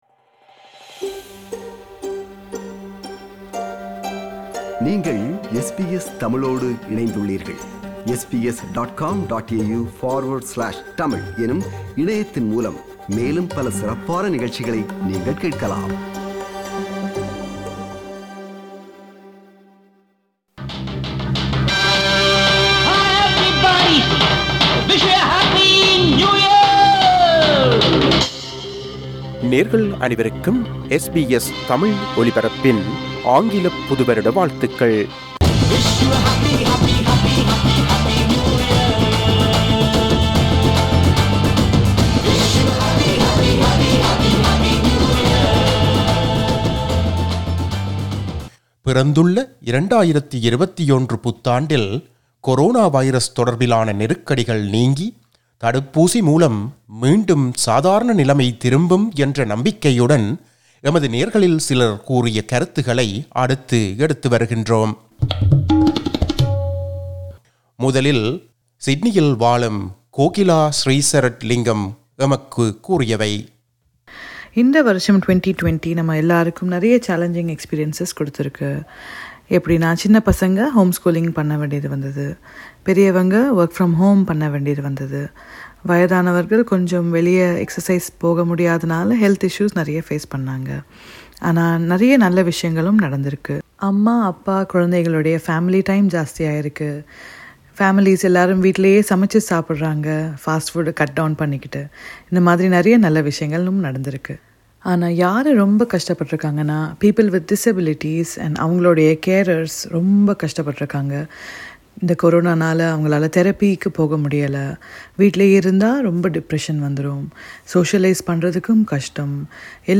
A few listeners share their New Year wishes